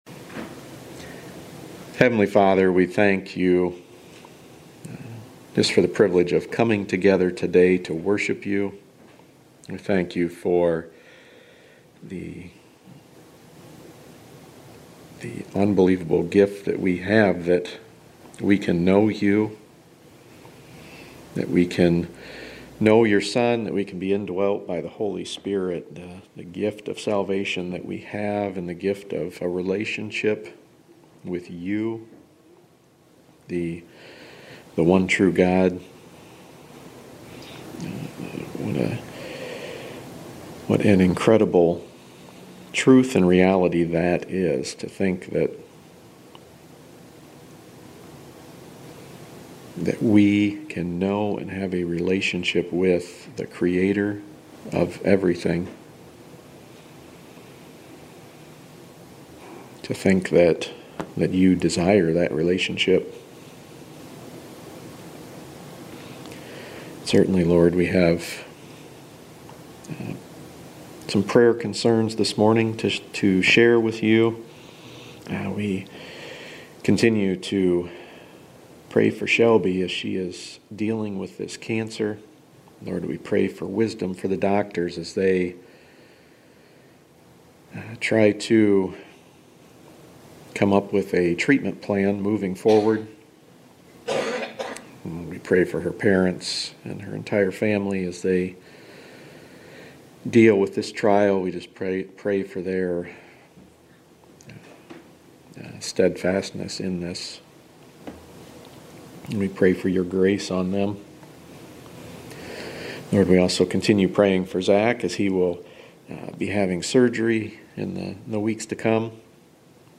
01-The-Flavor-Of-Relevance-Sermon.mp3